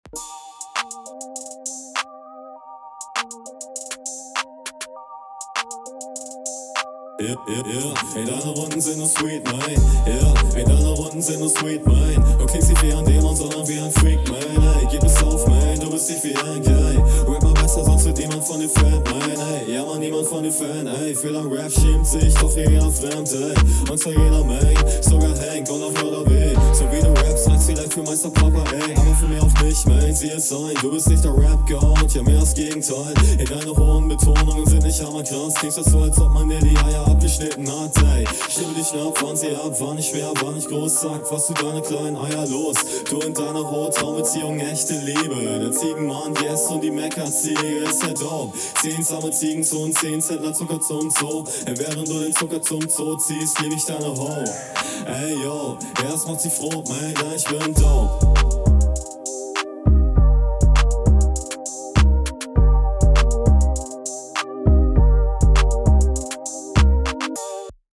Klingt super smooth und angenehm, allerdings, gerade am Anfang, sehr unverständlich.
Find den Flow und die Stimme recht angenehm, klingt auf jeden fall alles schon recht …